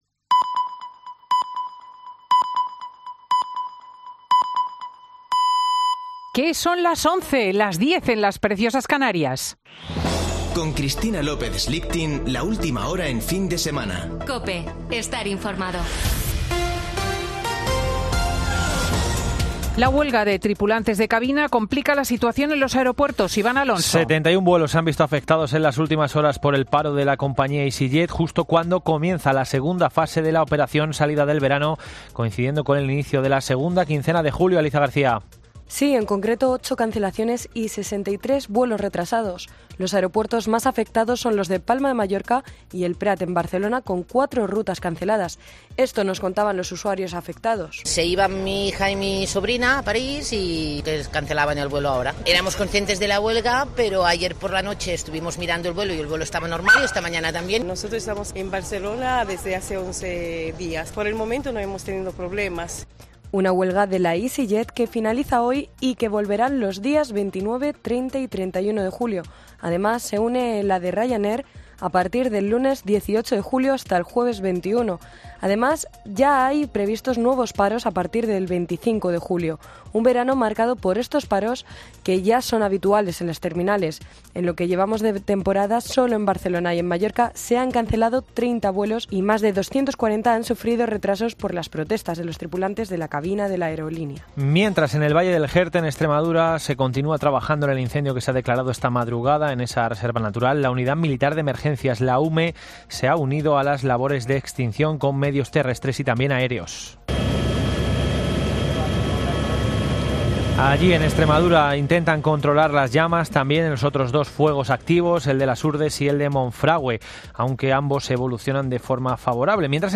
Boletín de noticias de COPE del 17 de julio de 2022 a las 11:00 horas